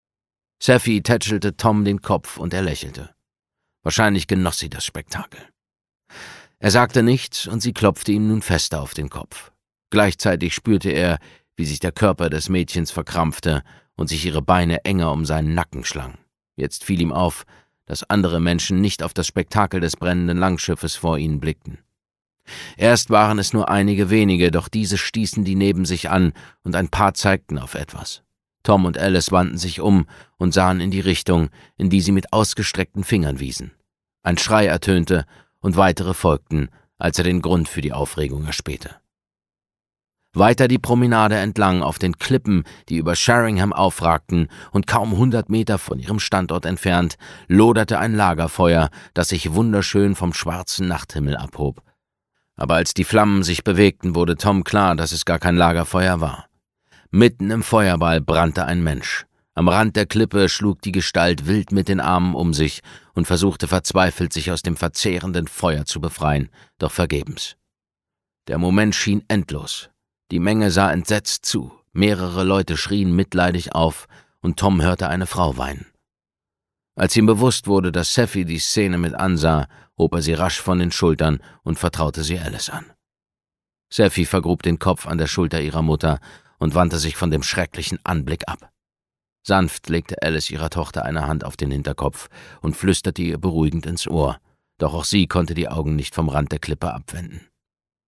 Infos zum Hörbuch